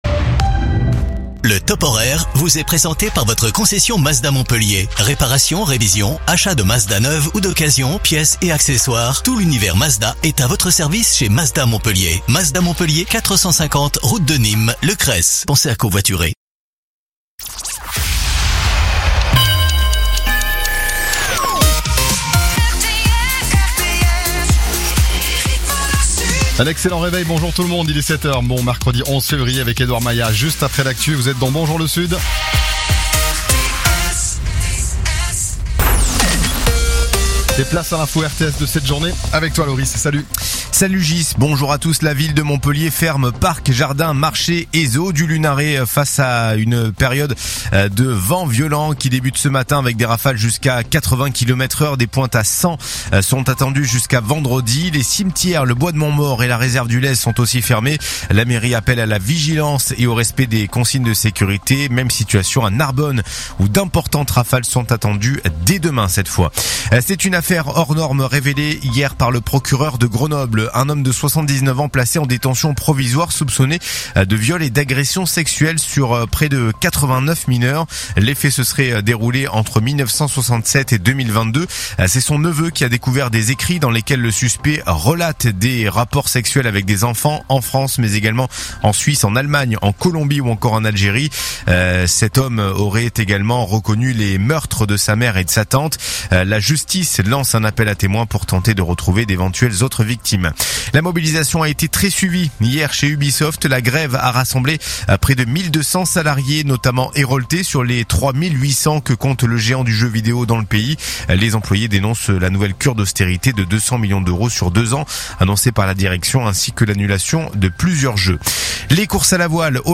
RTS : Réécoutez les flash infos et les différentes chroniques de votre radio⬦
info_mtp_sete_beziers_656.mp3